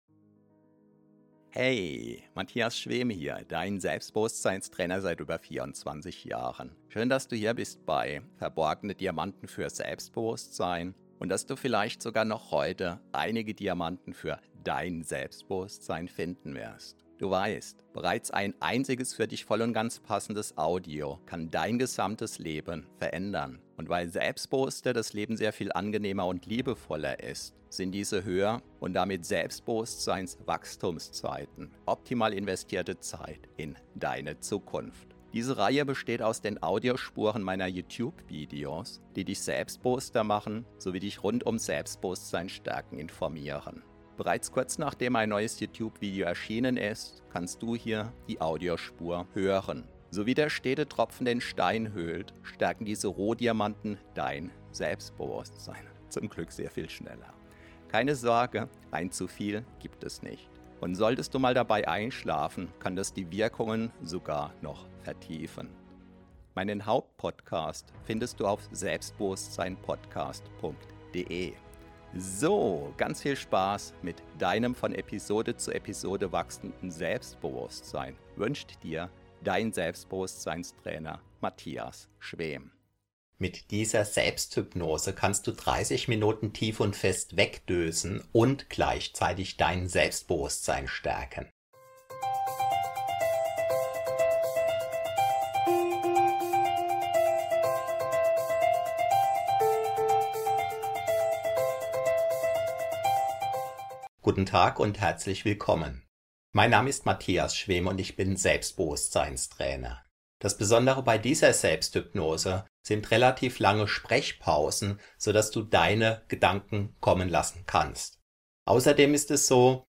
Powernap 30 min Meditation Mittagsschlaf zum Selbstbewusstsein stärken. Powernapping deutsch ~ Verborgene Diamanten Podcast [Alles mit Selbstbewusstsein] Podcast